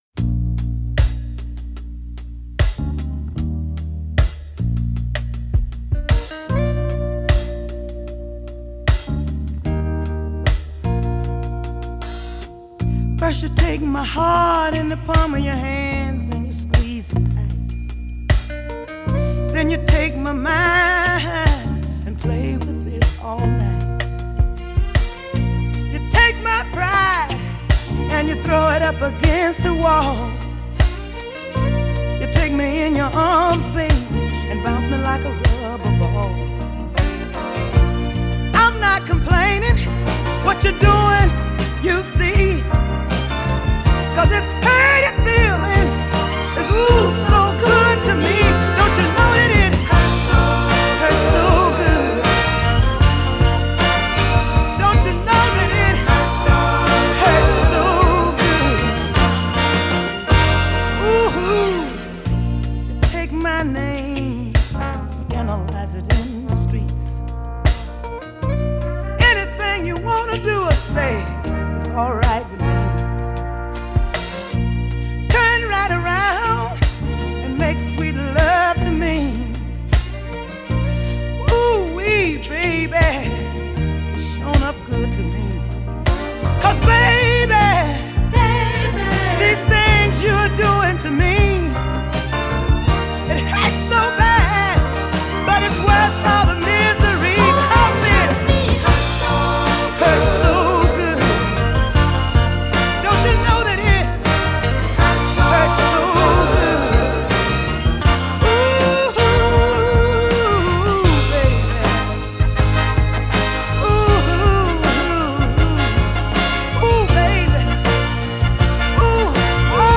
summed it up pretty well in her 1973 soul nugget